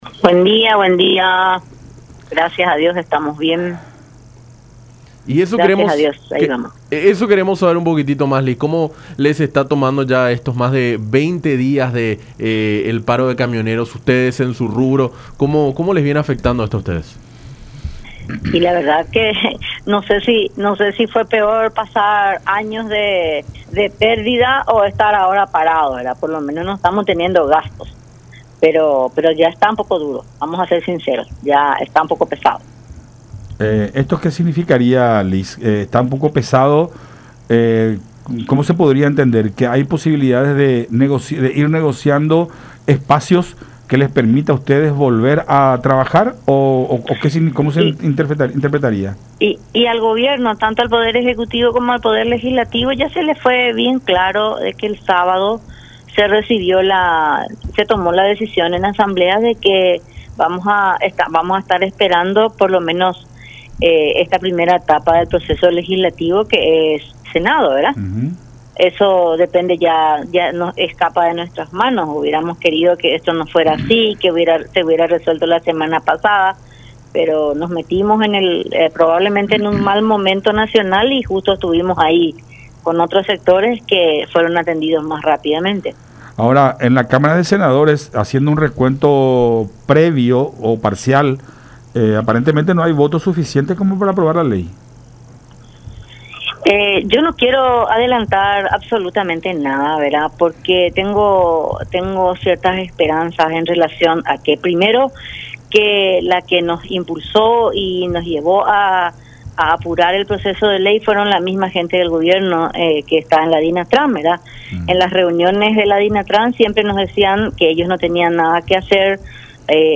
en diálogo con Enfoque 800 por La Unión.